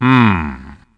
Play, download and share Luigi – "Hmm" original sound button!!!!
luigi-hmm.mp3